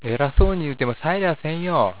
ためになる広島の方言辞典 さ．